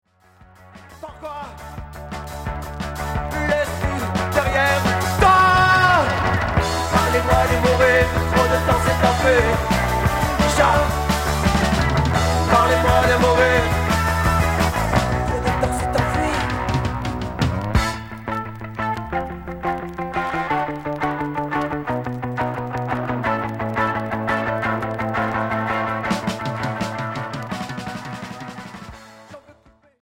Punk rock Unique 45t retour à l'accueil